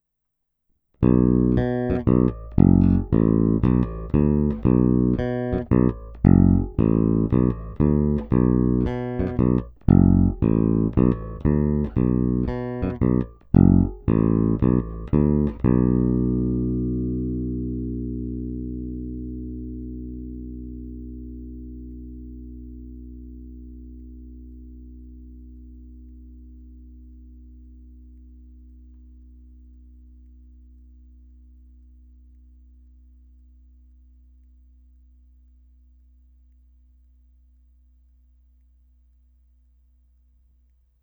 Jestliže zvukový projev lípy je obecně měkčí, temnější, jelikož se jedná o měkké dřevo, snímače tento projev upozadily, zvuk je naprosto klasický průrazně jazzbassový, s pořádnou porcí kousavých středů.
Není-li uvedeno jinak, následující nahrávky jsou provedeny rovnou do zvukové karty, jen normalizovány, jinak ponechány bez úprav.
Snímač u kobylky